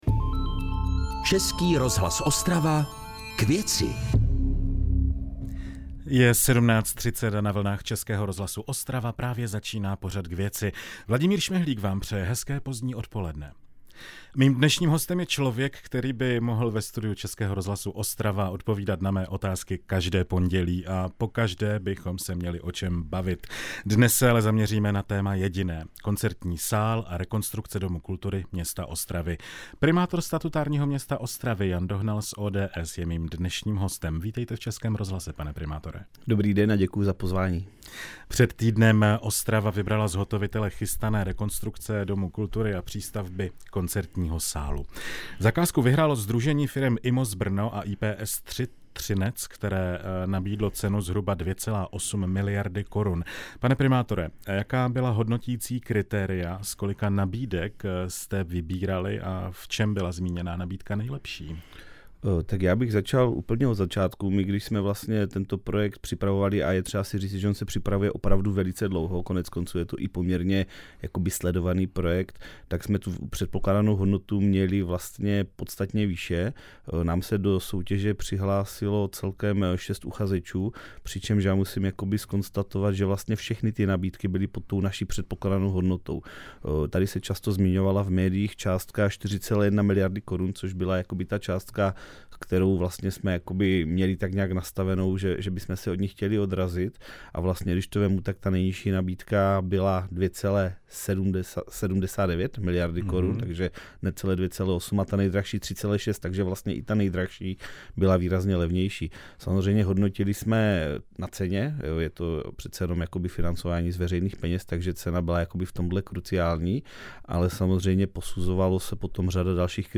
Jak probíhají přípravy na stavbu koncertního sálu v Ostravě? Ptali jsme se primátora - 04.03.2024